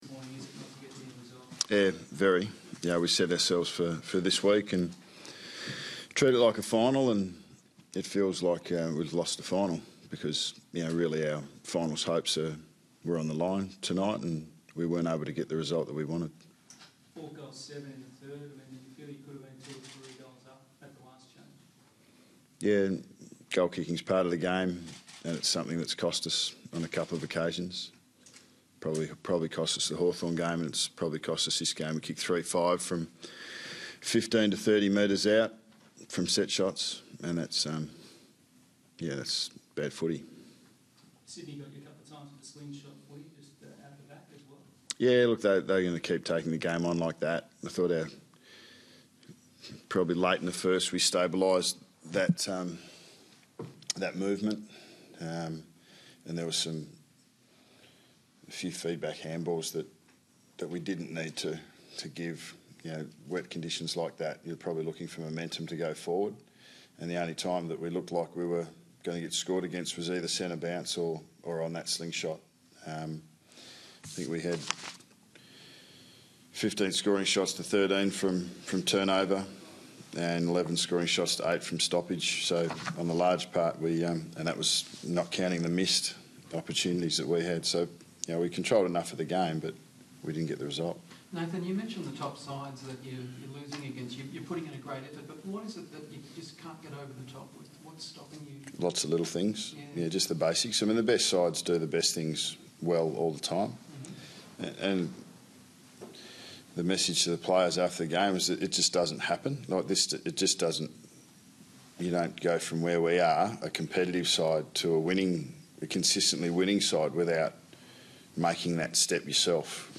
Listen to coach Nathan Buckley's post-match press conference following Collingwood's loss to Sydney in round 20, 2015.